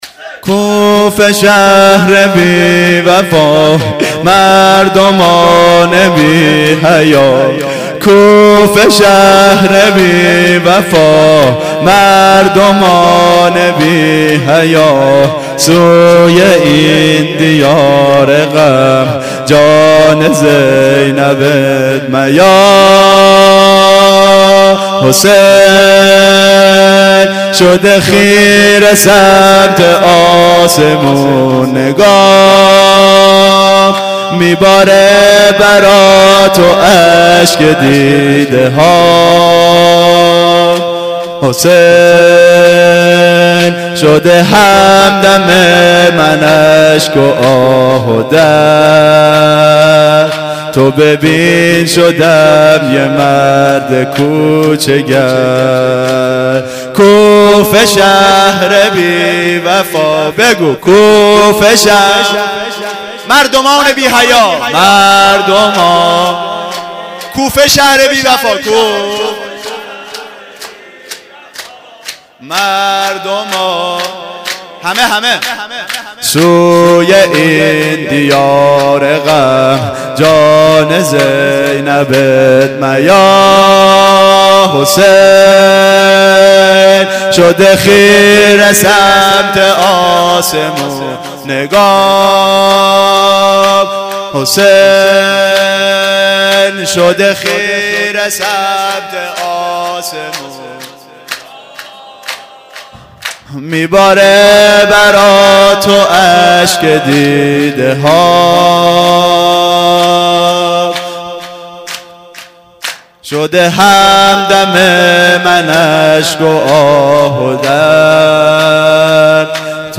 شور زمینه حضرت مسلم.mp3